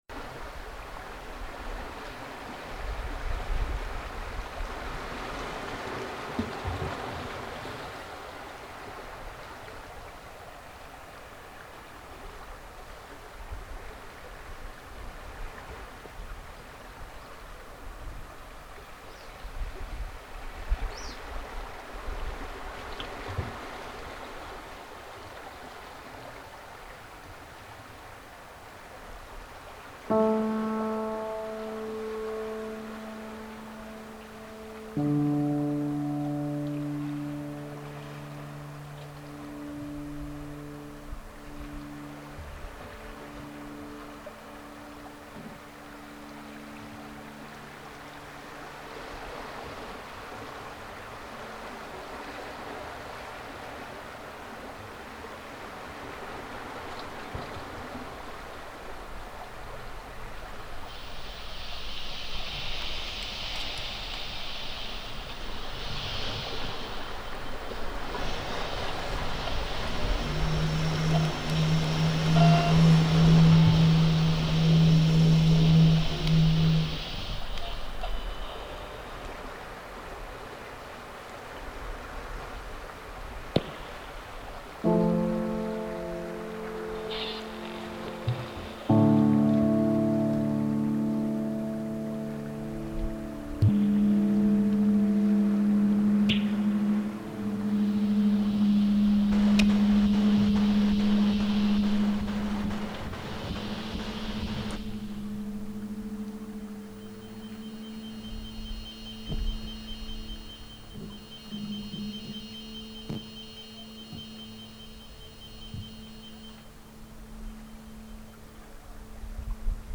improvised and contemporary music
electronic devices
contrabass recorder
microphone and amp
phonography
piano